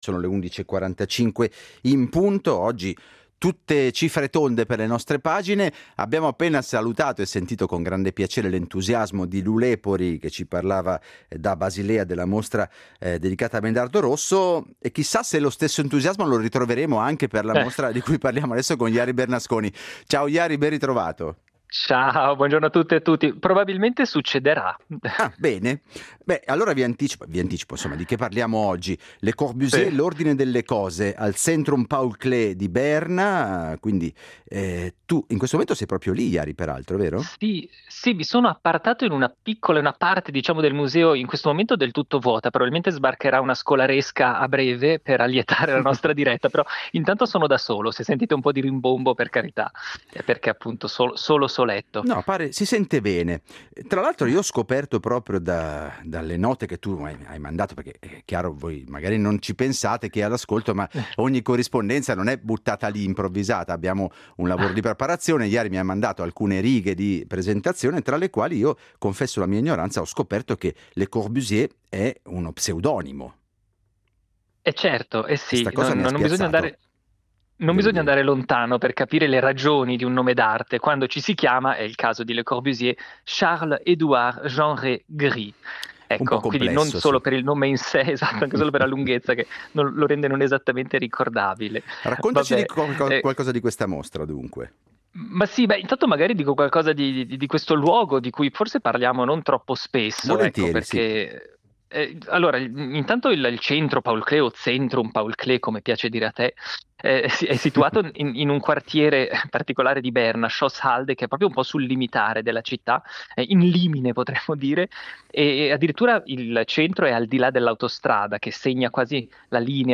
in visita alla mostra, ce l’ha raccontata proprio dalle sale dello ZPK.